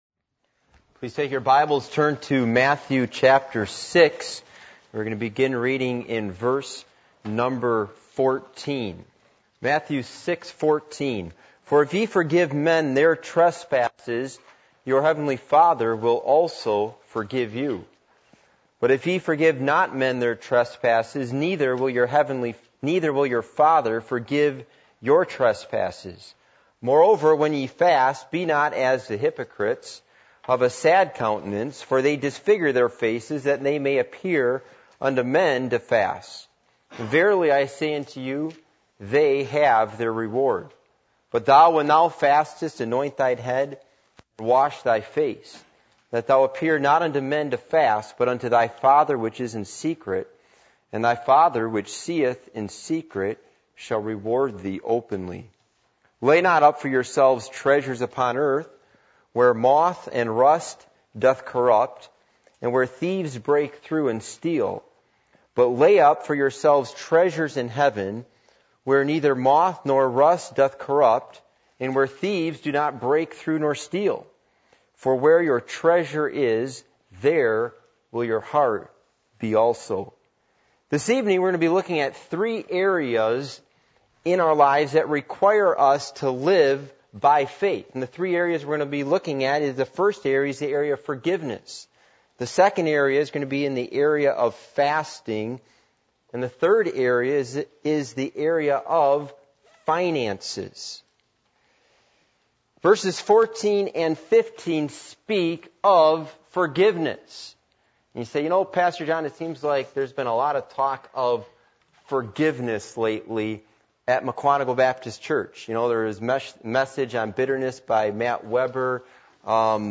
Passage: Matthew 6:14-21 Service Type: Midweek Meeting